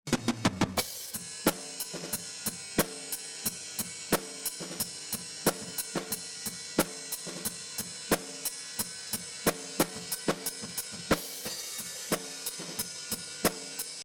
je prends maintenant cette source et je la compresse très fortement (avec un récupération de db perdu et cela donne donc ça :